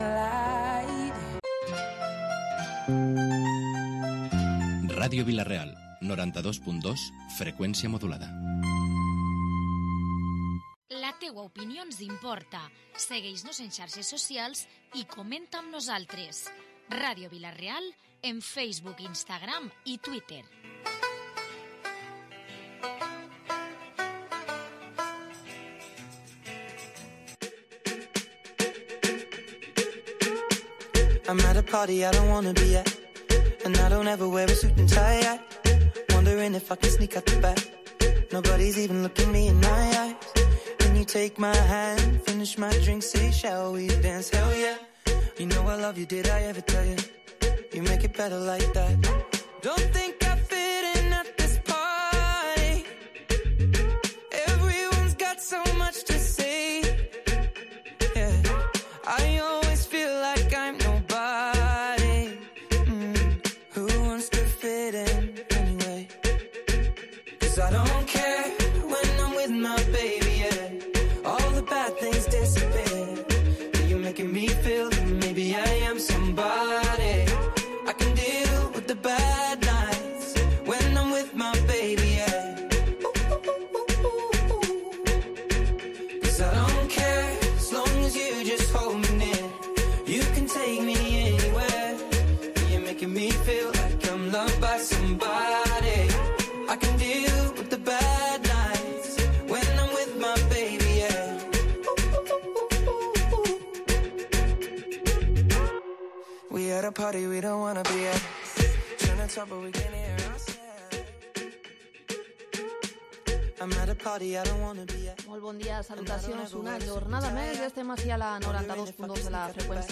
Hoy en Protagonistes hemos conocido más detalles sobre la obra autobiográfica que el teatro de la Resistencia llevará este sábado al Auditori Músic Rafael Beltrán Moner. Por otra parte, hemos escuchado en balance y las propuestas para el Mercat Central de Vila-real. Y hemos tenido tertulia política.